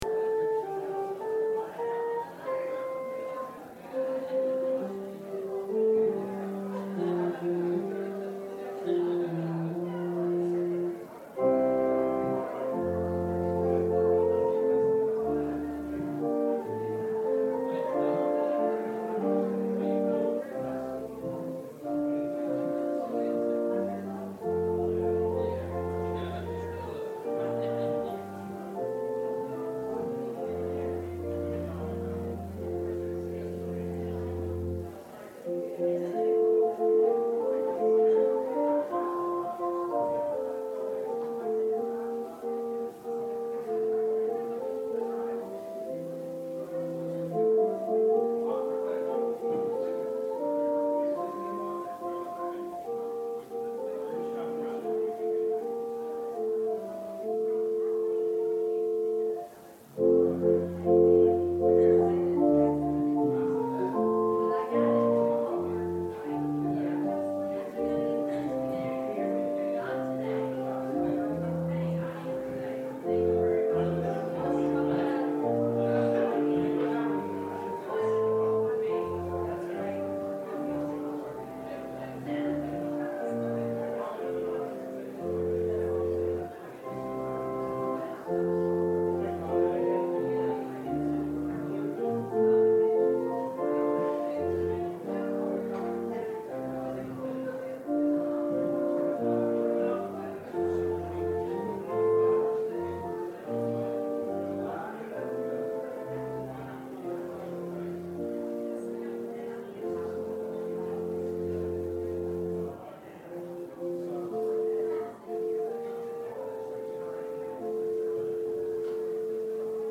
Ephesians 2:4-10 Service Type: Sunday Worship Don't confuse Luck with Grace.